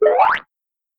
BWOINK Download